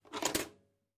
На этой странице вы найдете подборку звуков видеомагнитофона: характерное жужжание двигателя, щелчки кнопок, фоновые шумы аналоговой записи.
Звук: извлекаем кассету VHS из видеомагнитофона